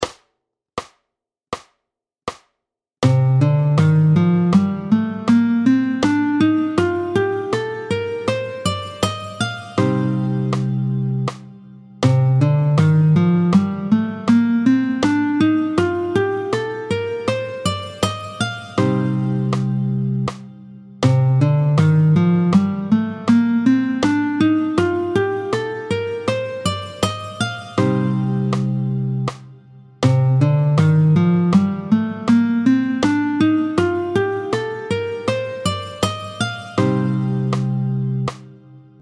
Le mode (et le doigté II) : do ré mib fa sol la sib do est appelé le Dorien.
27-02 Le doigté du mode de Do dorien, tempo 80